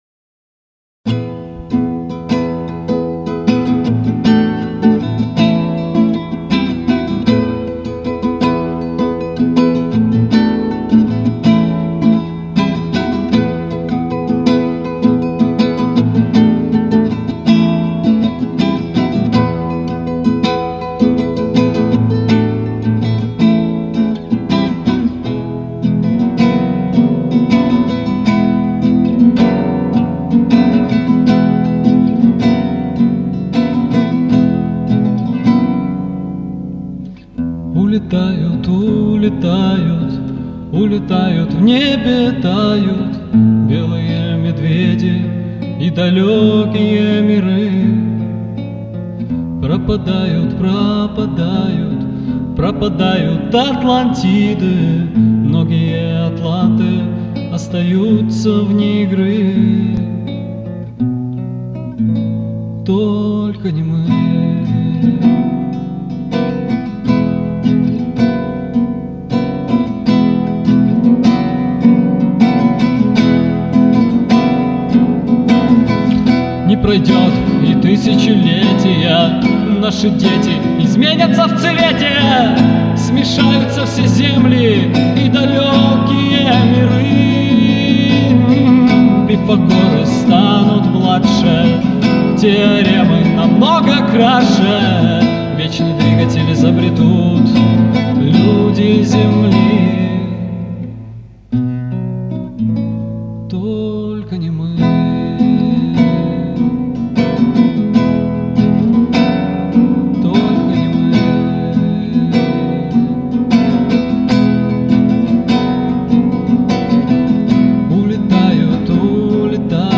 Демоальбом - г. Москва